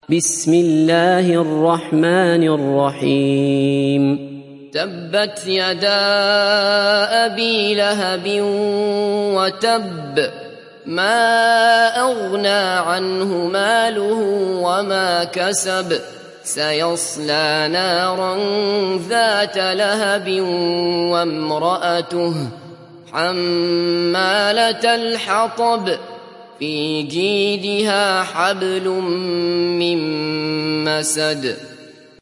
دانلود سوره المسد mp3 عبد الله بصفر روایت حفص از عاصم, قرآن را دانلود کنید و گوش کن mp3 ، لینک مستقیم کامل